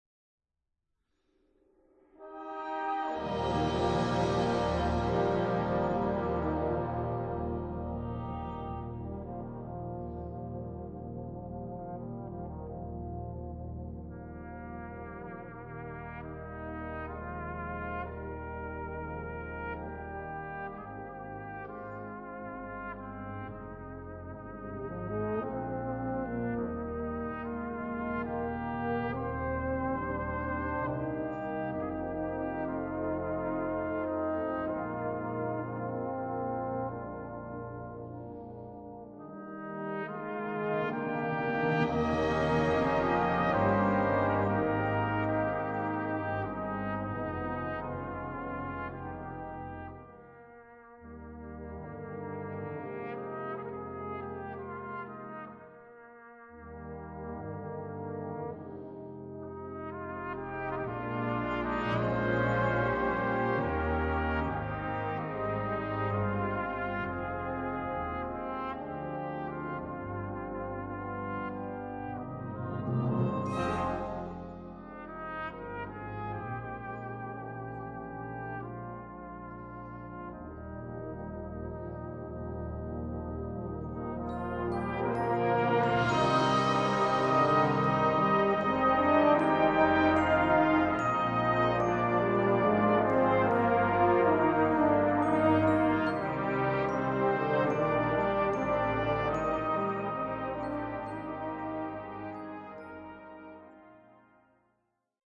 Répertoire pour Brass Band